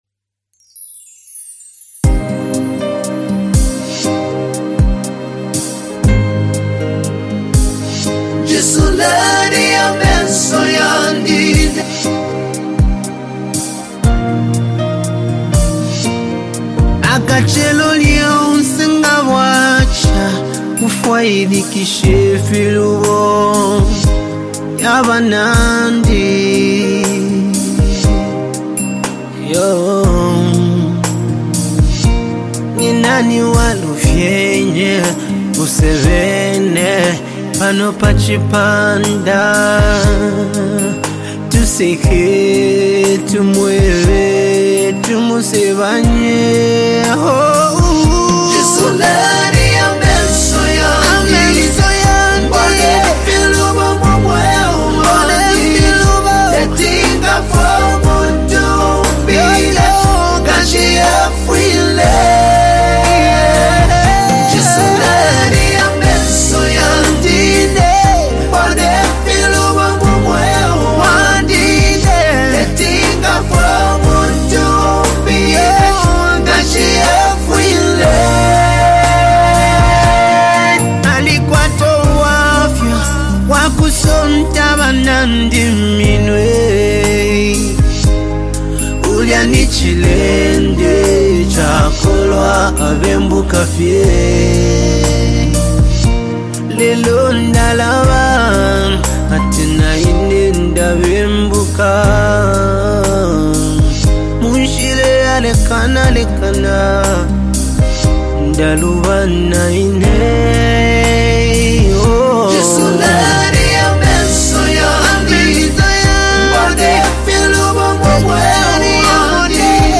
WORSHIP SONG
ZAMBIAN GOSPEL MUSIC